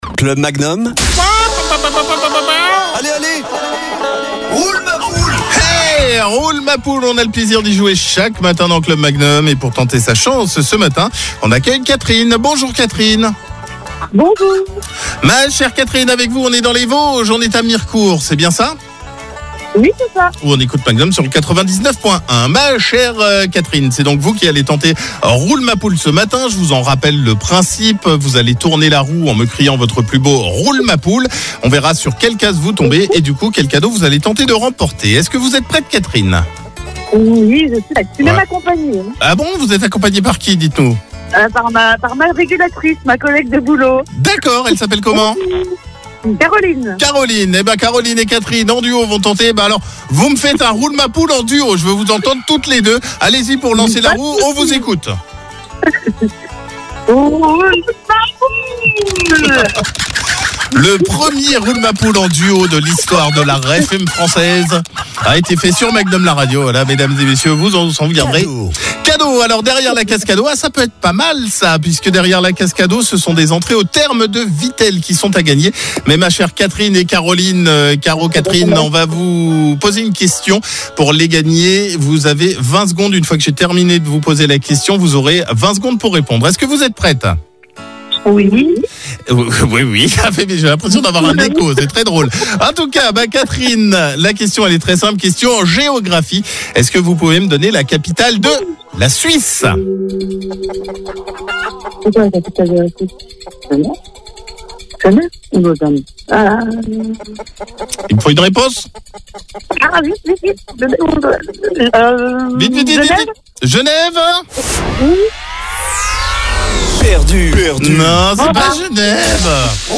Tournez la roue en criant « Roule ma poule » , plus vous criez fort, plus la roue va tourner.